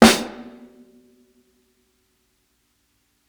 60s_SNARE AND HH.wav